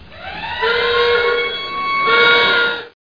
SIREN00.mp3